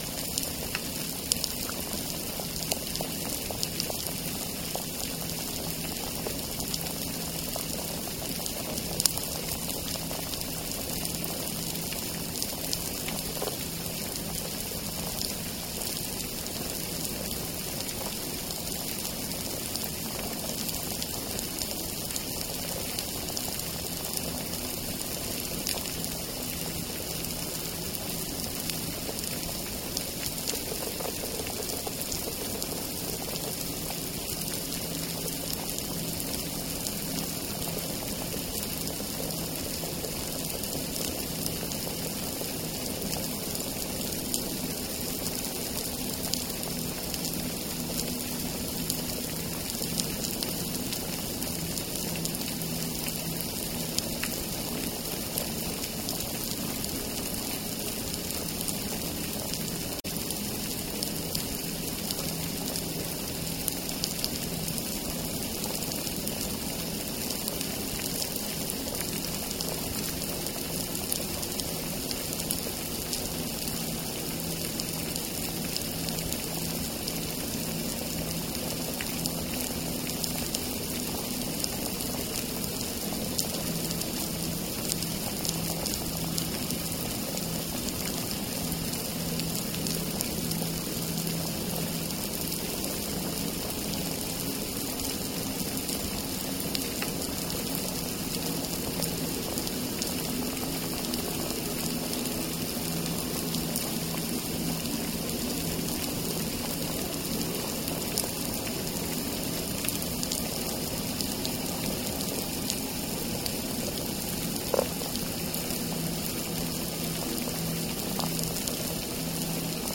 Demonstration soundscapes
anthropophony
biophony